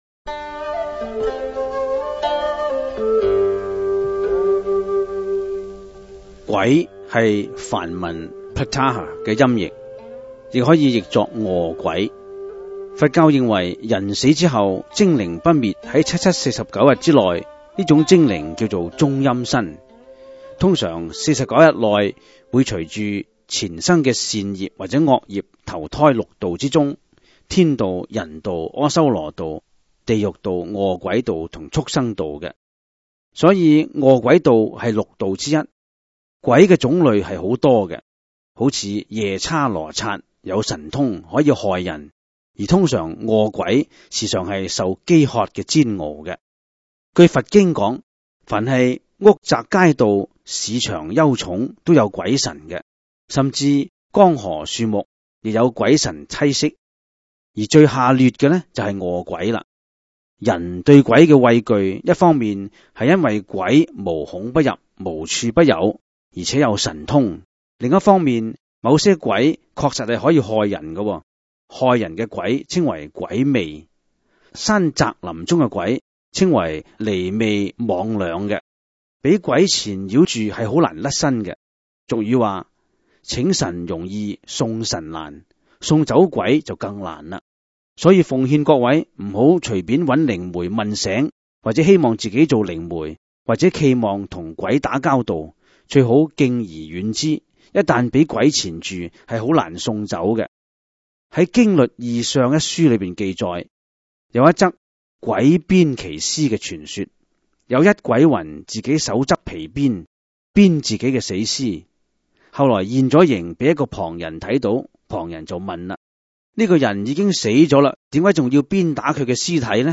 第 二 十 辑    (粤语主讲  MP3 格式)